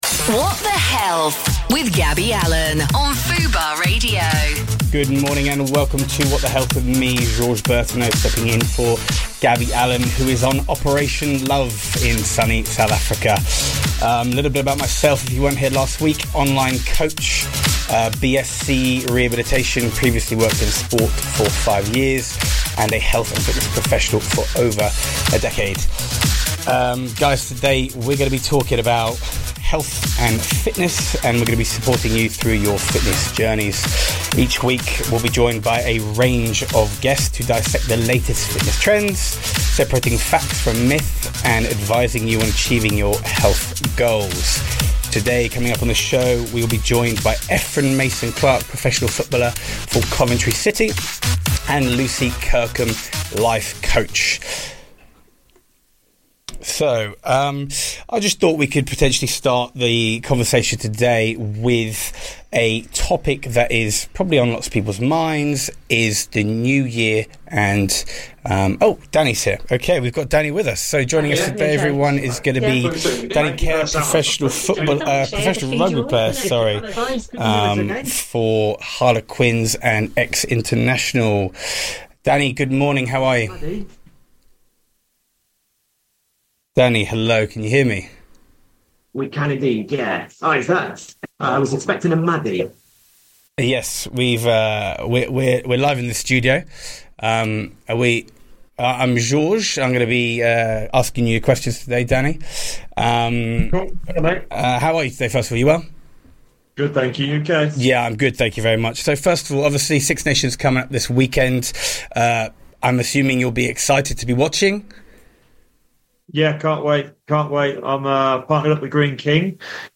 He was joined by Danny Care, professional rugby player, who spoke about the Six Nations starting this week and his game predictions.